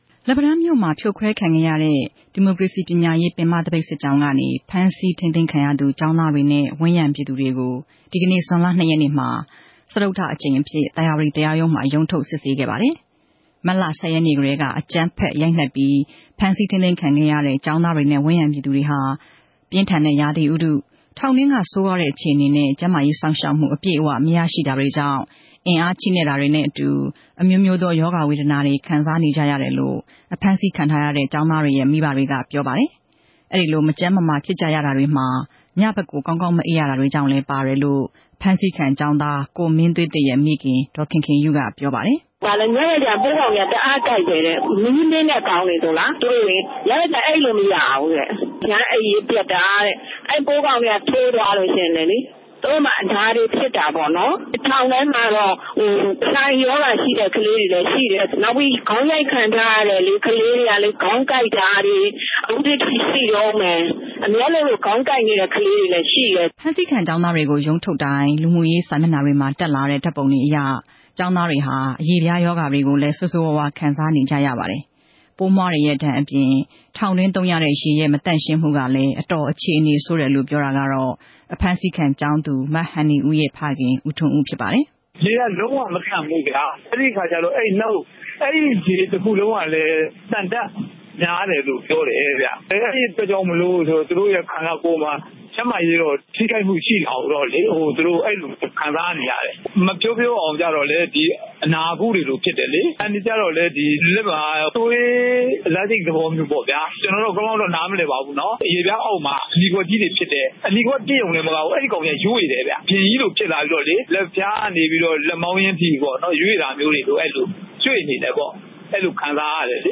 စုစည်းတင်ပြချက်